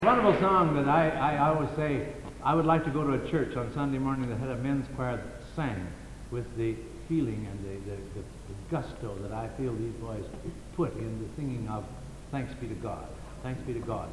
Location: Plymouth, England
Genre: | Type: Director intros, emceeing